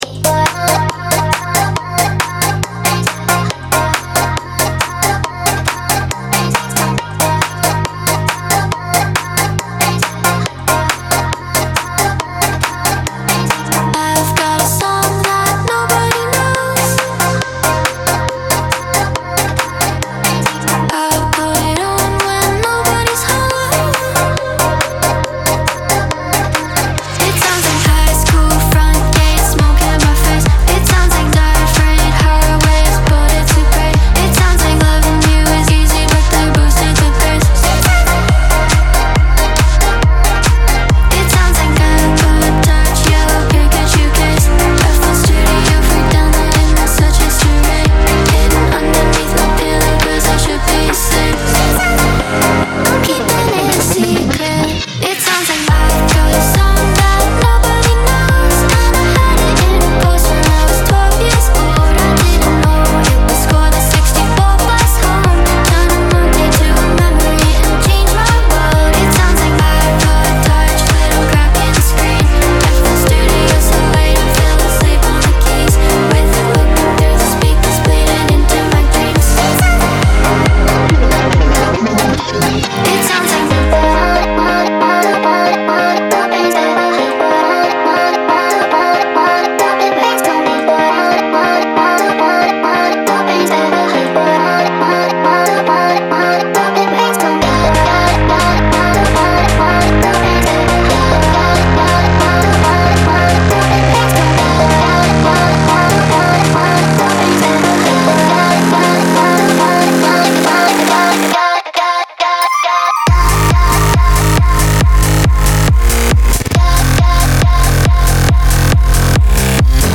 BPM138-138
Audio QualityPerfect (High Quality)
Electro song for StepMania, ITGmania, Project Outfox
Full Length Song (not arcade length cut)